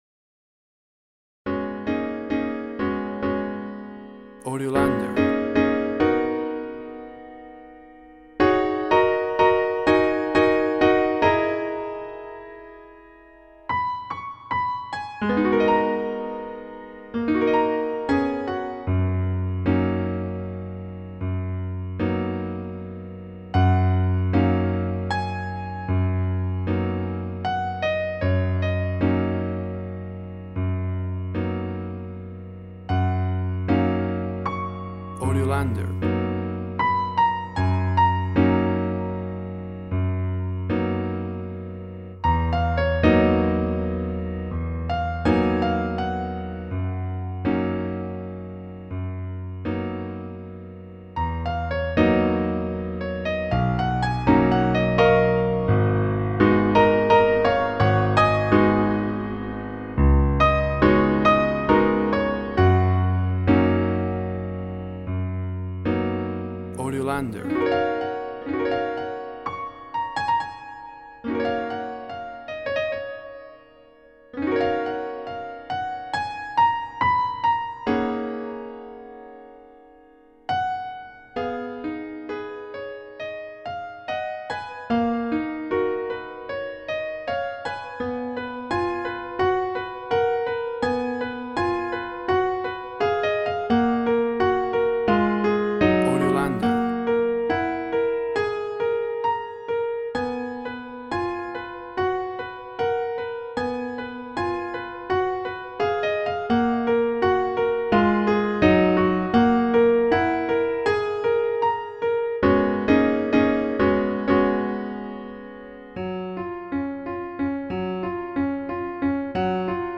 WAV Sample Rate 16-Bit Stereo, 44.1 kHz
Tempo (BPM) 65